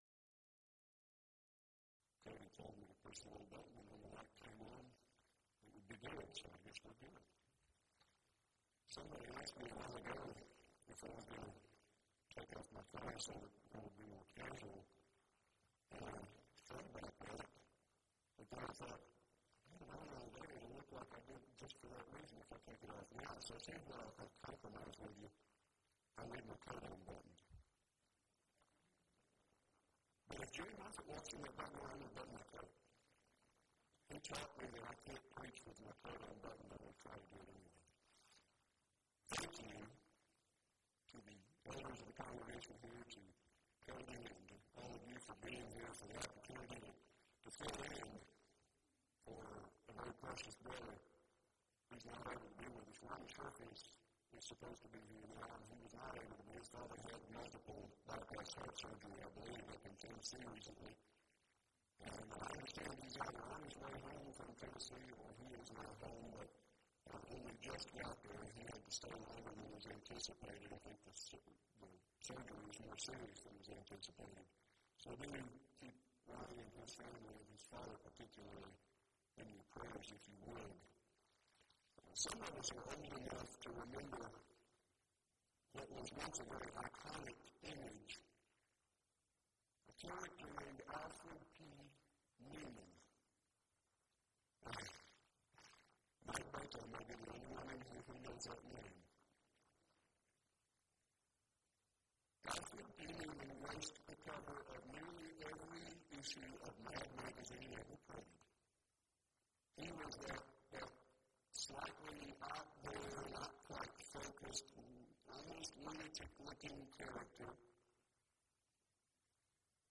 Event: 2nd Annual Arise Workshop